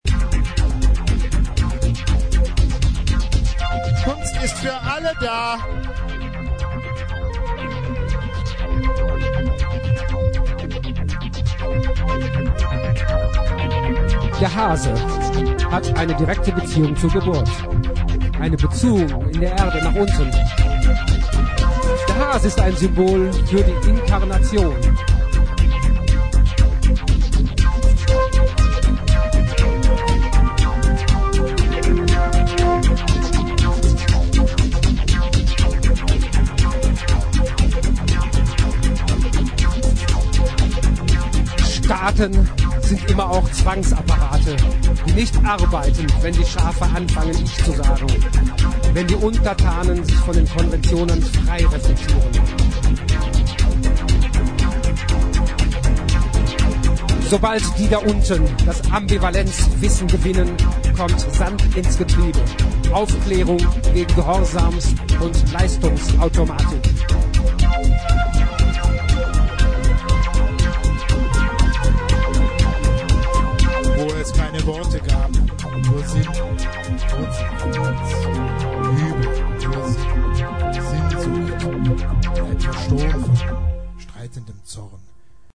Nacht der Museen Düsseldorf 2003